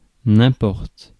Prononciation
Prononciation France: IPA: [ɛ̃.pɔʁt] Le mot recherché trouvé avec ces langues de source: français Les traductions n’ont pas été trouvées pour la langue de destination choisie.